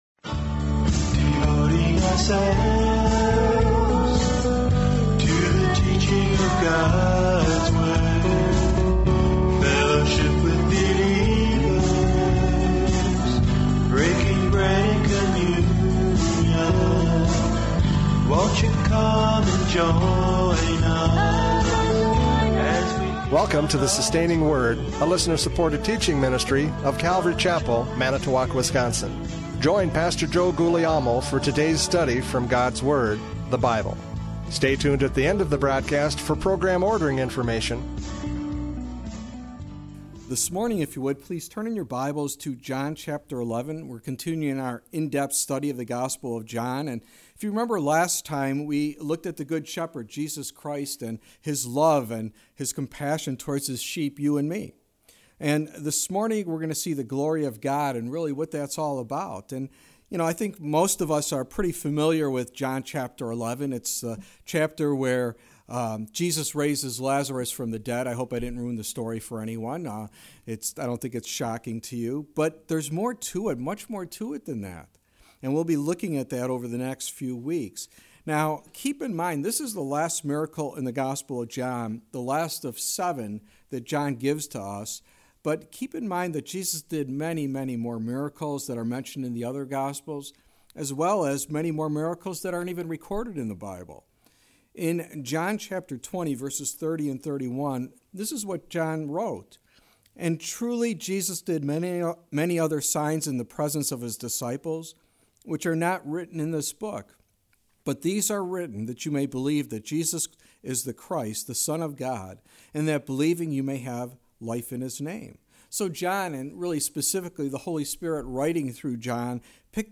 John 11:1-4 Service Type: Radio Programs « John 10:22-42 The Good Shepherd Speaks!